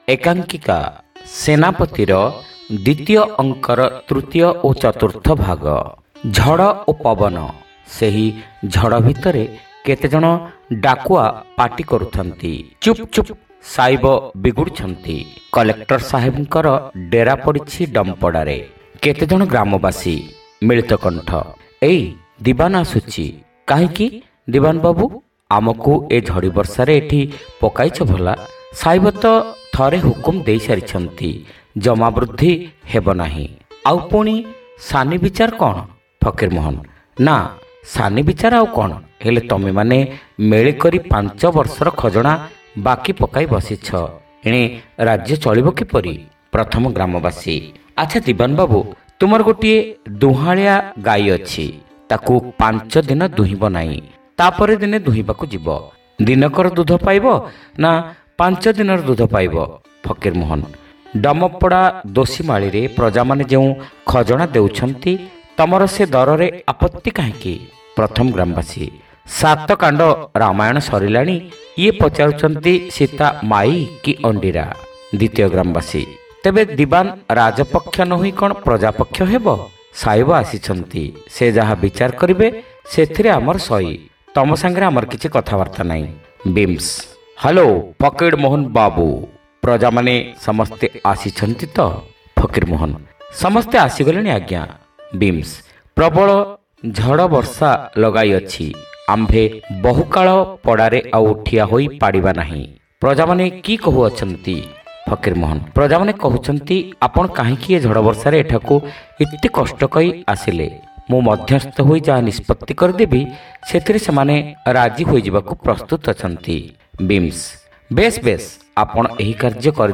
ଶ୍ରାବ୍ୟ ଏକାଙ୍କିକା : ସେନାପତି (ଚତୁର୍ଥ ଭାଗ)